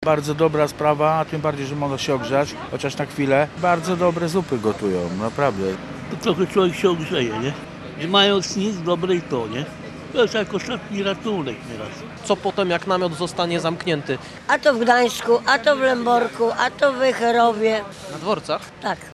Z potrzebującymi rozmawiał nasz reporter.